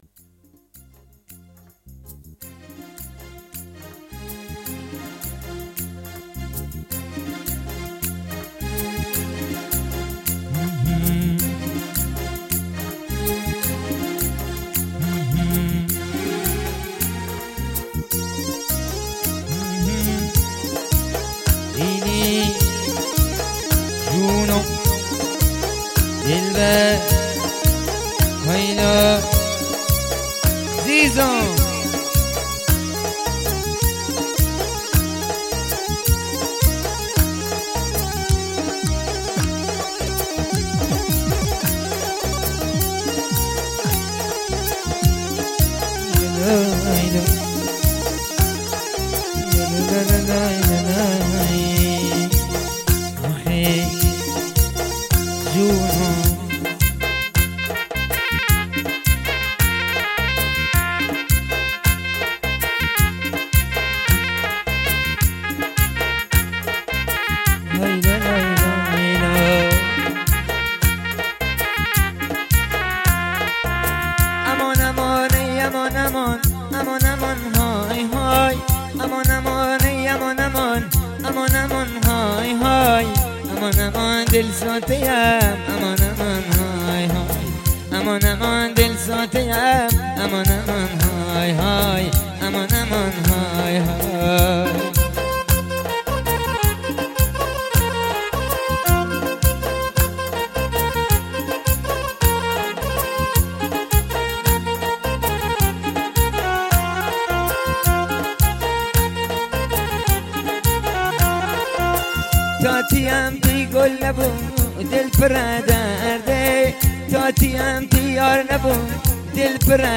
موزیک بختیار شاد